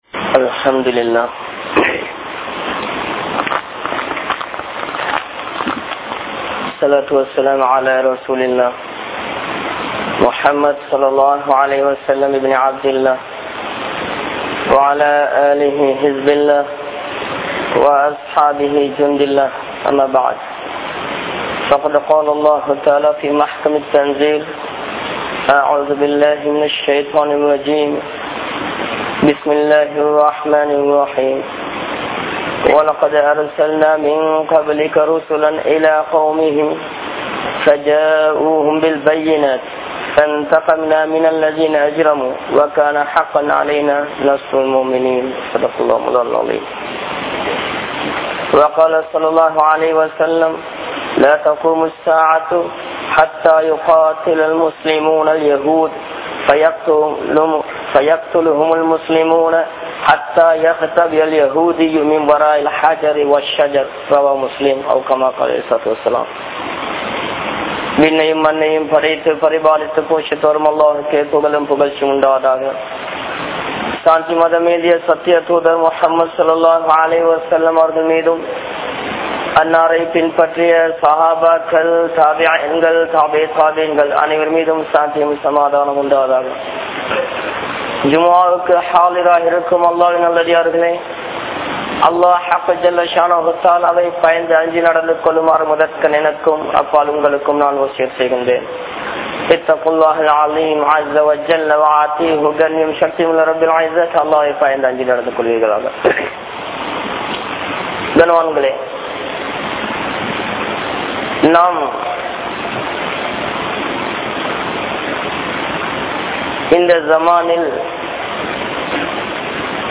Indru Maraikap Pattu Varum GAZA`vin Unmaihal | Audio Bayans | All Ceylon Muslim Youth Community | Addalaichenai